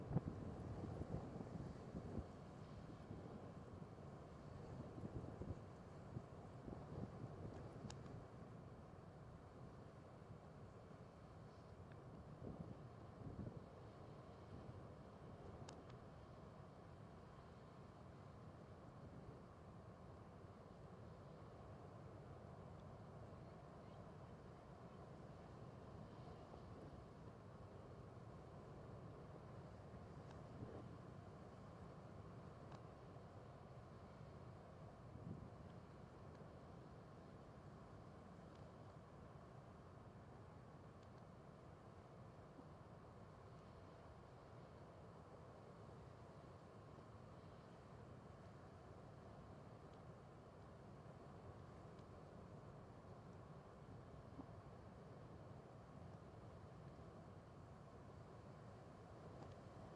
死亡的海岸 " LOBEIRAS BEACH ORTF
Tag: 大洋 大海 沙滩 海浪